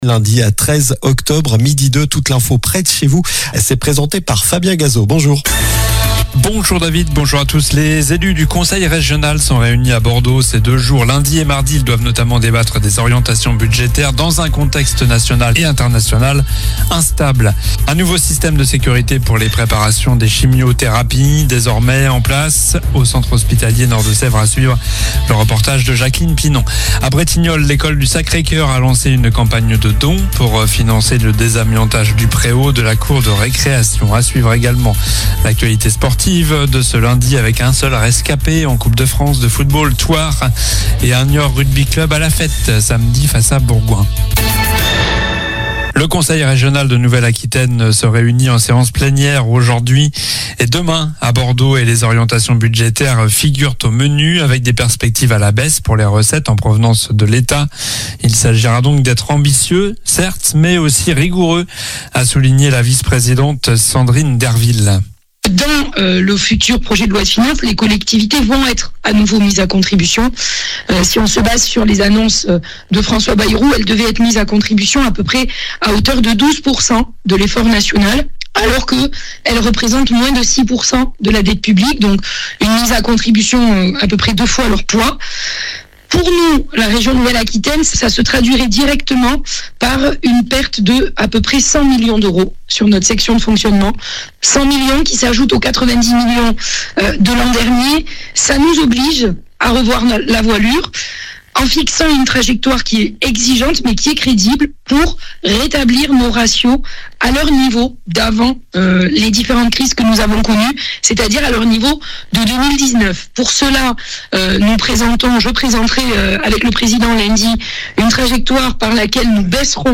Journal du lundi 13 octobre (midi)